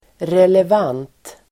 Uttal: [relev'an:t (el. -'ang:t)]